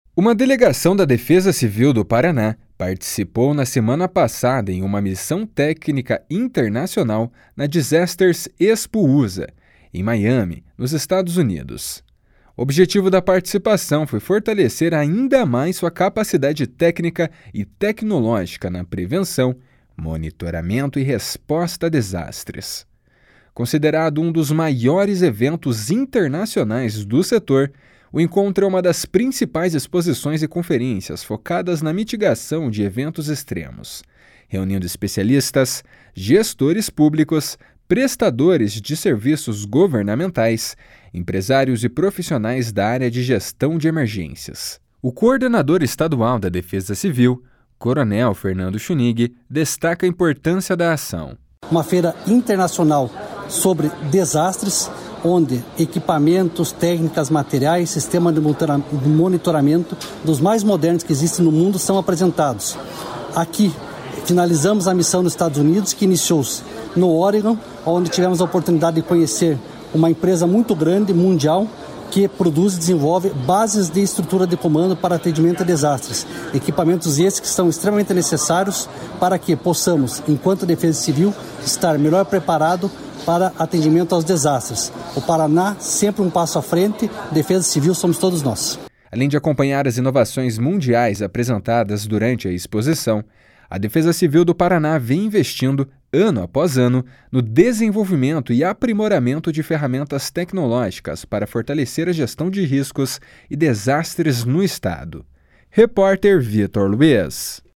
O coordenador estadual da Defesa Civil, coronel Fernando Schunig, destaca a importância da ação. // SONORA FERNANDO SCHUNIG //